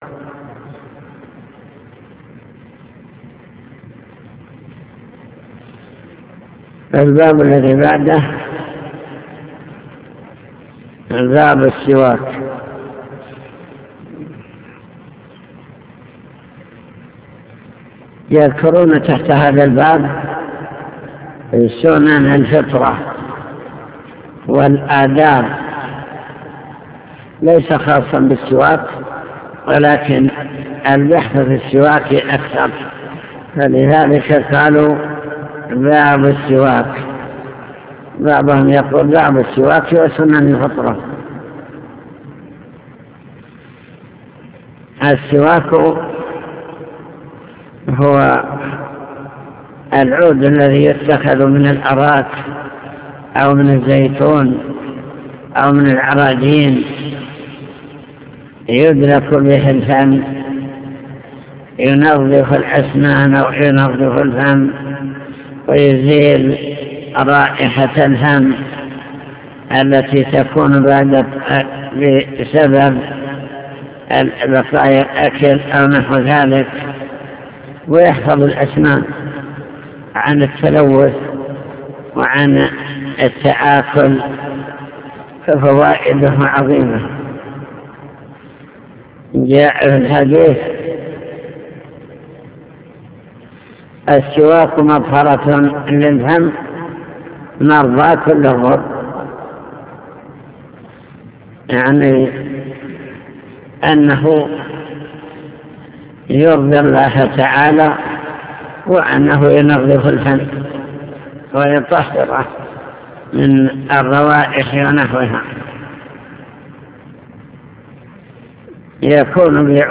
المكتبة الصوتية  تسجيلات - كتب  شرح كتاب دليل الطالب لنيل المطالب كتاب الطهارة باب في السواك